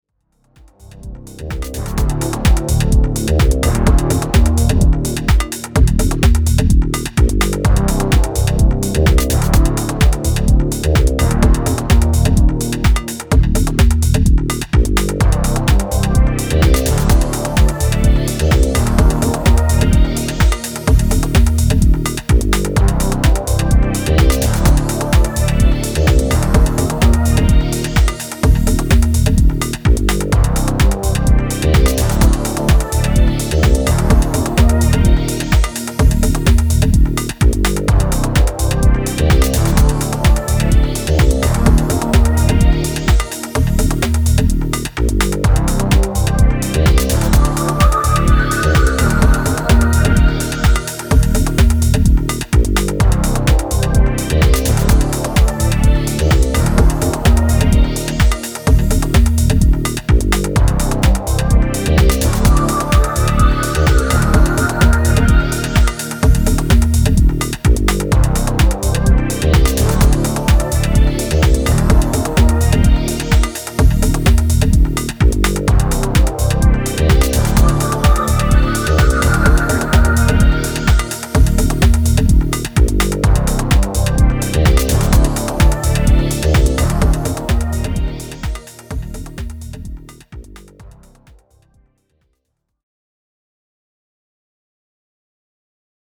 leftfield house & techno releases